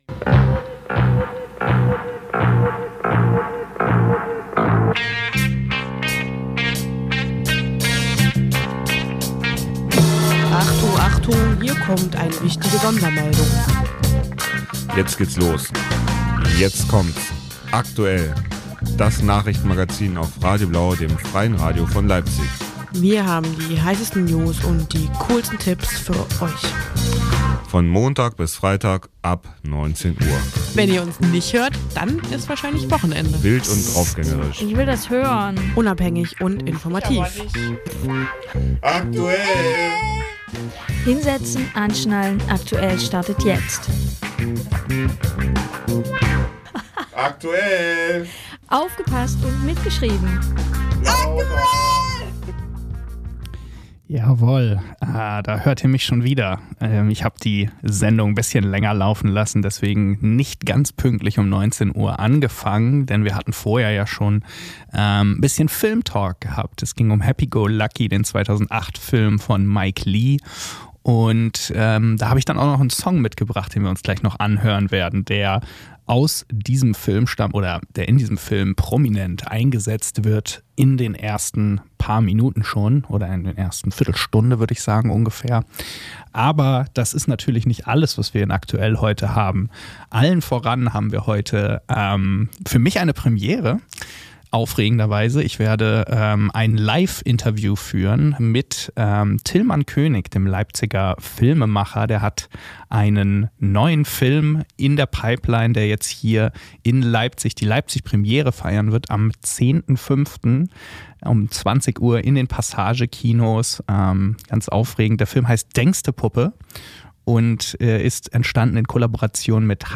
Das wochentägliche Magazin am Abend, mit Interviews und Beiträgen aus Politik und Kultur sowie Veranstaltungstipps.